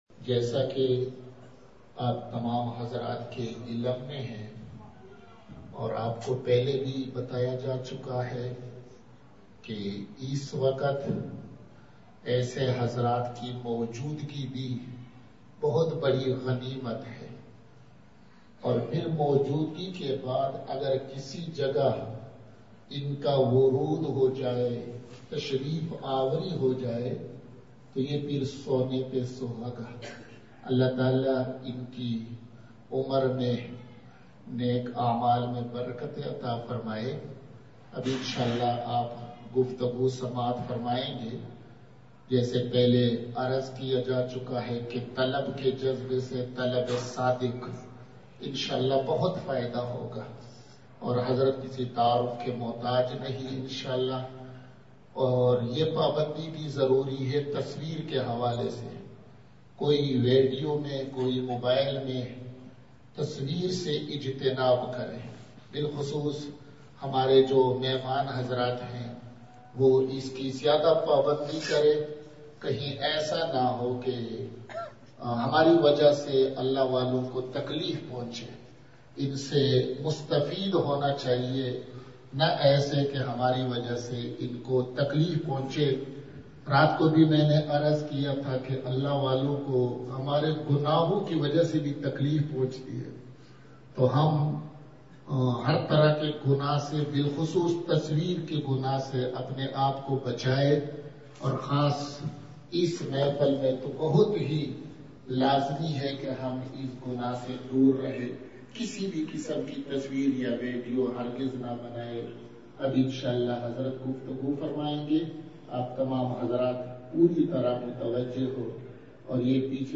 بمقام:۔مدرسہ ابن عباس کوہسار کالونی ٹیکسلا
بہت ہی درد دل سے اشکبار آنکھوں سے روتے ہوئے والدین اور استاد کے ادب انتہائی سبق آموز نصیحتیں ارشاد فرمائی آخر میں دعا۔۔